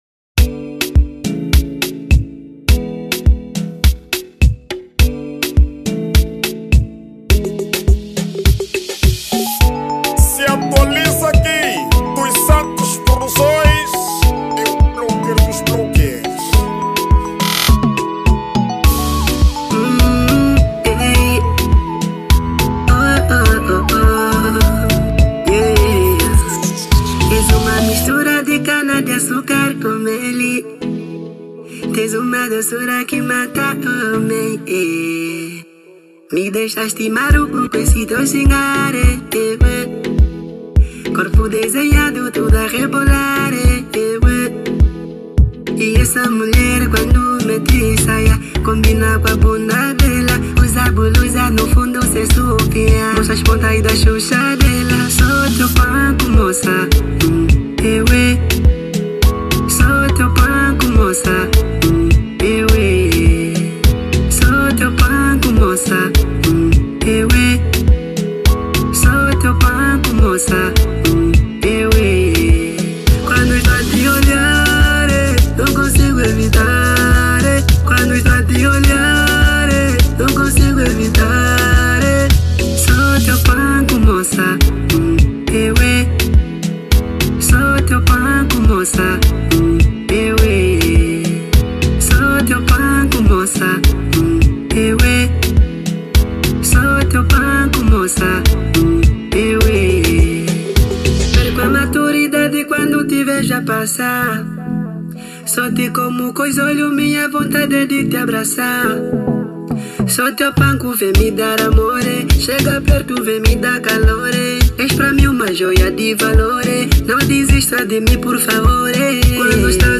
Categoria:  Zouk